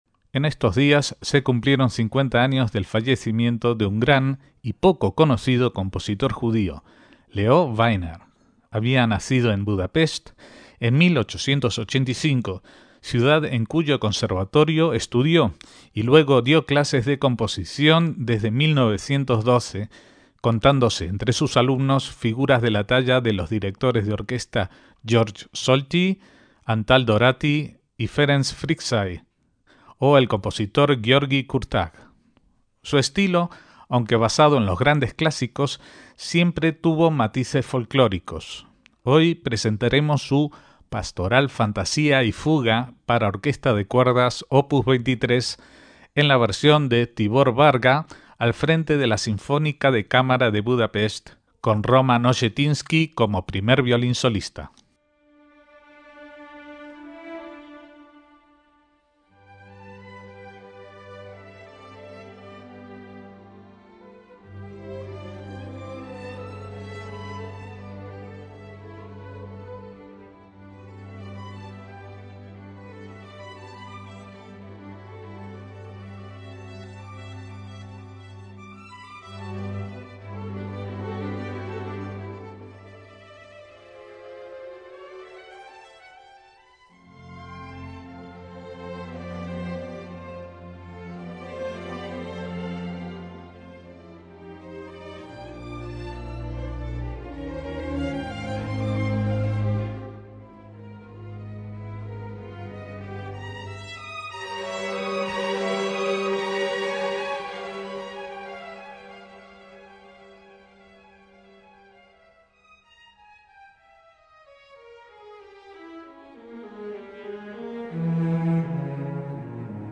MÚSICA CLÁSICA
neoclásica